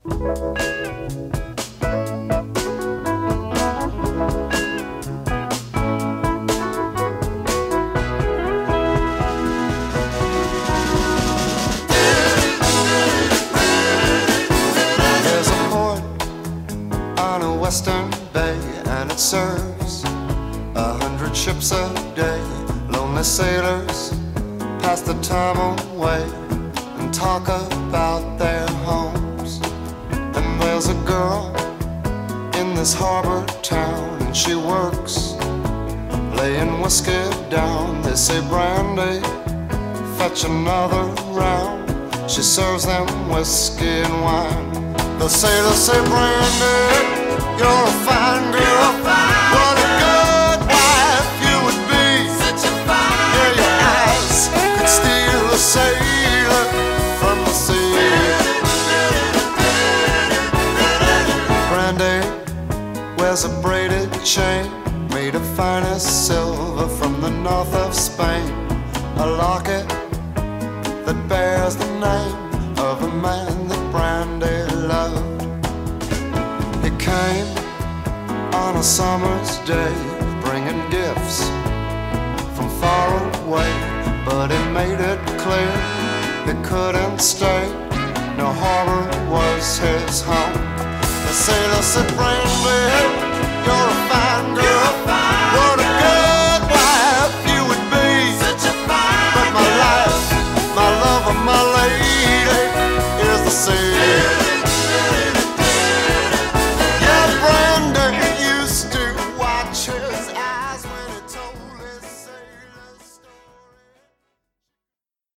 BPM123-127
Audio QualityMusic Cut